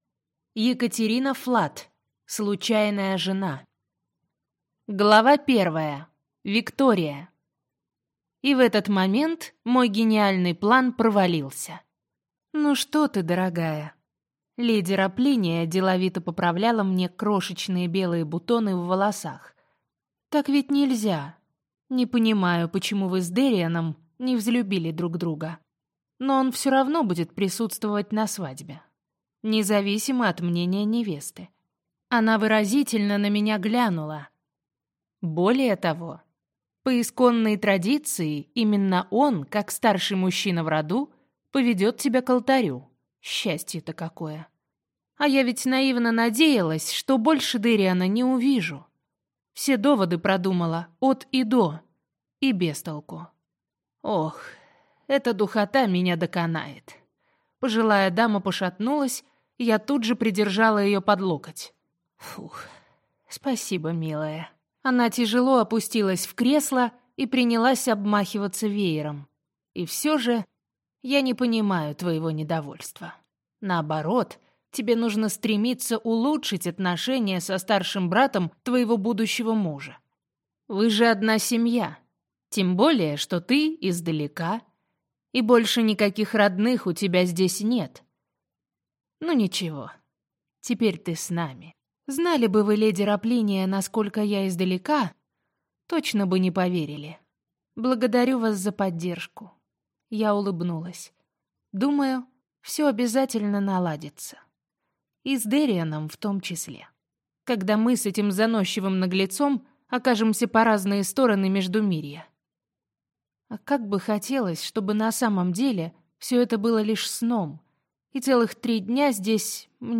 Аудиокнига Случайная жена | Библиотека аудиокниг